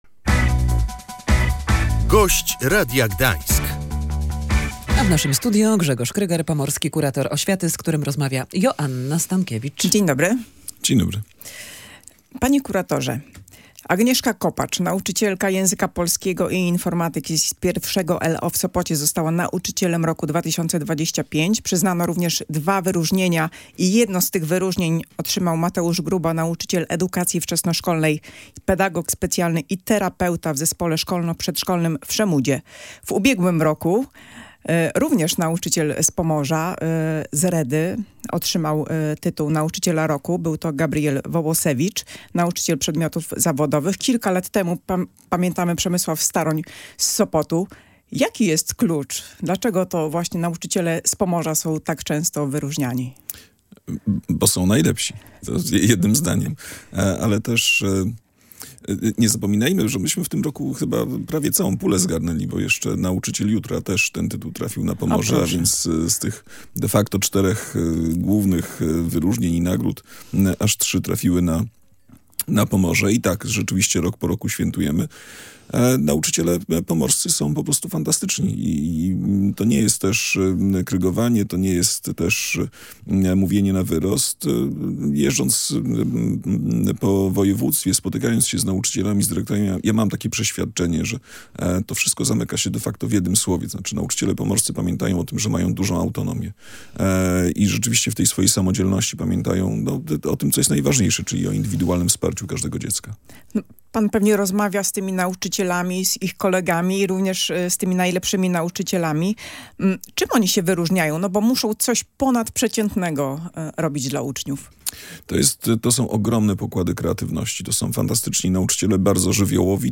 – Powinien to być przedmiot obowiązkowy – mówił w Radiu Gdańsk pomorski kurator oświaty Grzegorz Kryger.
Gość Radia Gdańsk dodał jednocześnie, że statystyki dotyczące frekwencji na lekcjach edukacji zdrowotnej nie odbiegają od tych dotyczących wychowania do życia w rodzinie.